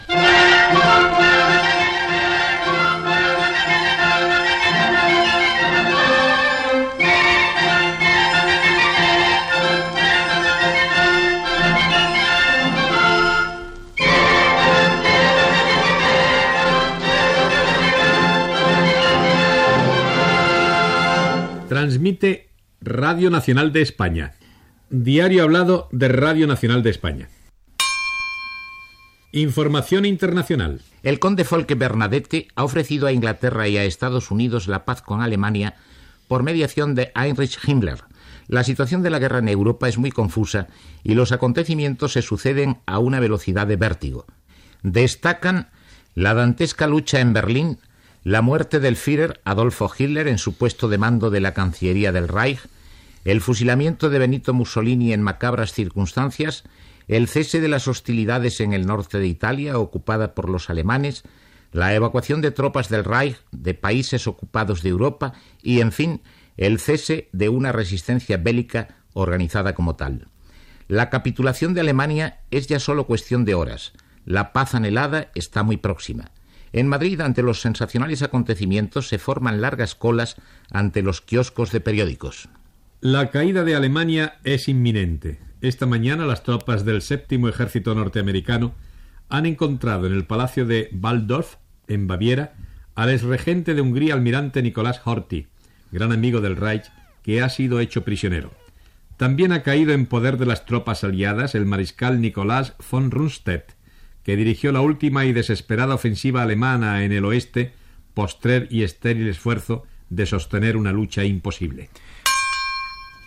Sintonia de l'emissora, informació de fets produïts als últims dies de la Segona Guerra Mundial: lluita a Berlín, mort d'Adolf Hitler, afusellament de Benito Mussolini, les tropes americanes detenen a l'almirall Miklós Horthy, a Baviera (Alemanya), i al mariscal Nicolas von Rundstedt.
Informatiu
Recreació feta, cap a l'any 1977, amb motiu dels 40 anys de Radio Nacional de España, per dos locutors d'aquella època.